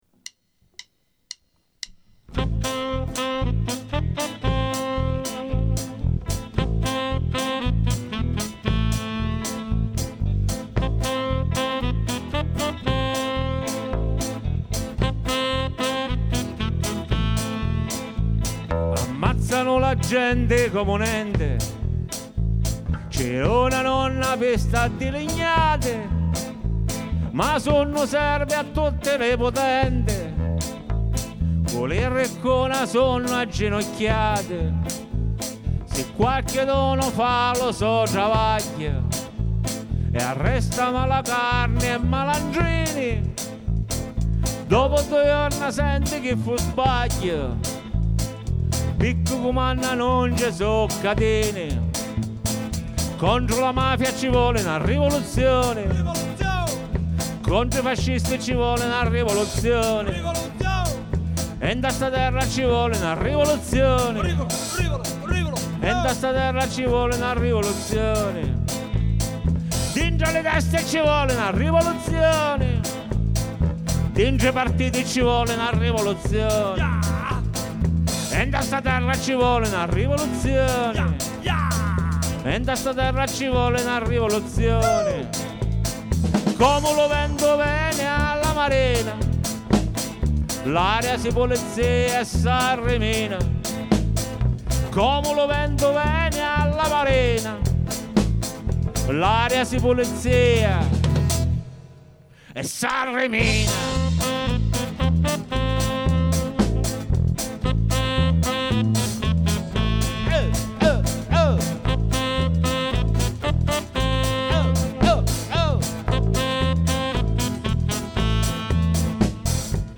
Buon ascolto - se volete ascoltarli così, grezzi e sporchi.